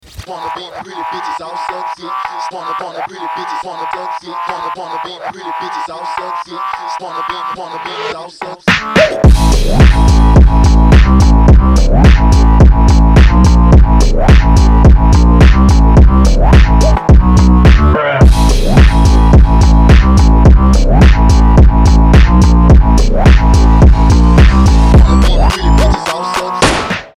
• Качество: 320, Stereo
мужской голос
жесткие
качающие
четкие
фонк
Стиль: phonk